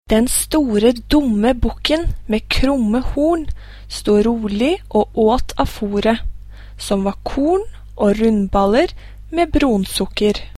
Ny side 1 Lytt til dialekteksemplene nedenfor.